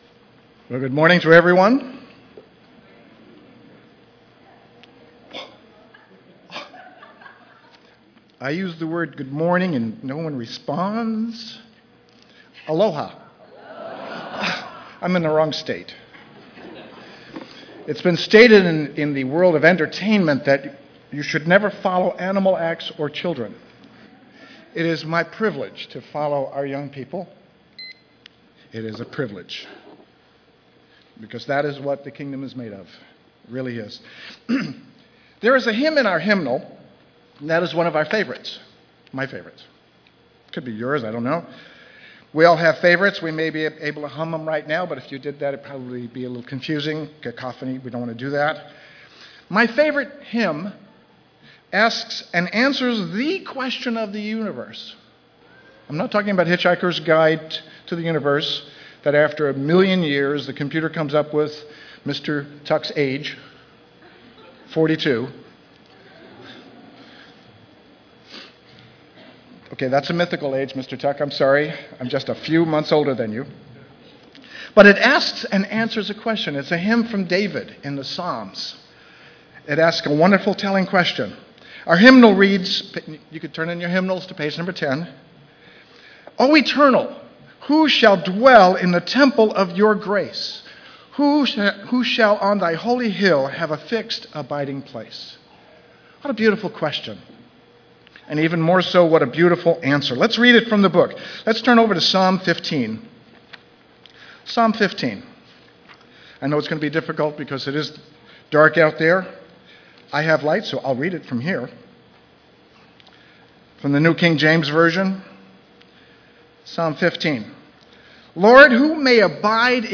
This sermon was given at the Maui, Hawaii 2013 Feast site.